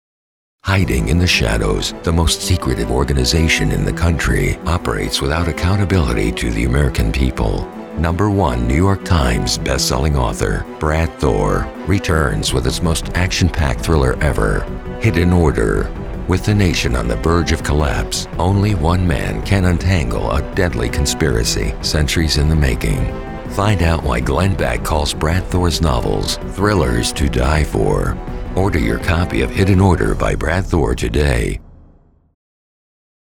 BOOK TRAILER VOICE OVER
BOOK TRAILER VOICE OVER DEMO
BookTrailer.mp3